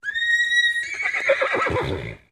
Whinnies
Horse Whinnies & Blows 3